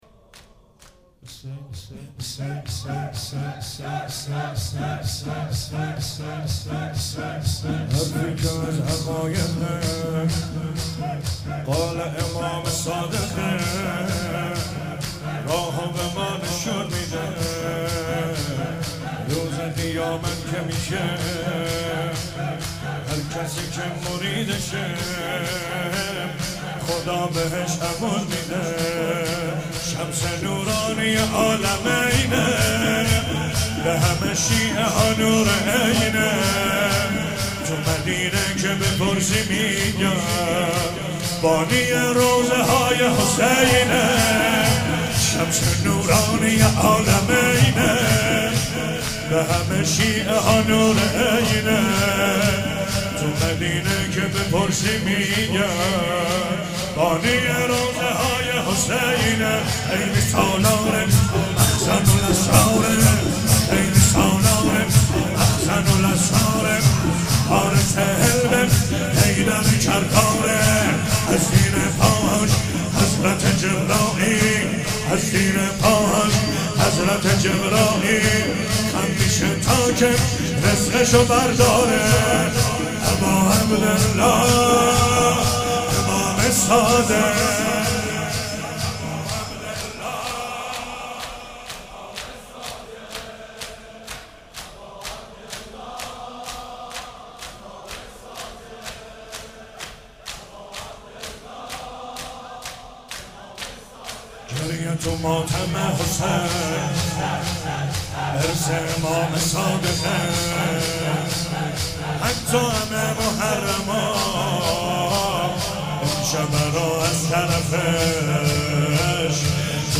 هفتگی - 21 تیر 97 - شور - حرفی که از حقایق قال امام صادق